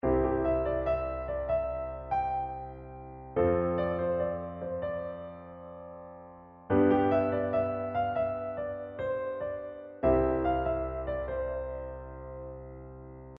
In questa guida ti spiego principalmente la parte armonica, mentre gli strumenti rimangono limitati alla chitarra, al basso e alle percussioni.
L'armonia di base del motivo
Solo tre accordi, come vedi, che rispondono a uno schema molto semplice ma efficace.
Il pezzo che ho scritto è nella scala di Do.
Questo è il motivo per cui diciamo che è una progressione I – IV – V – I.
tutorial-songwriting-semplice-accordi.mp3